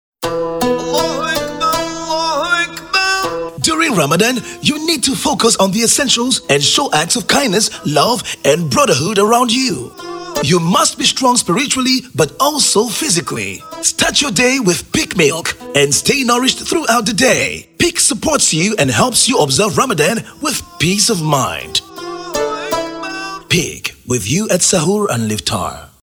spot-radio-promo-gambie_157_5.mp3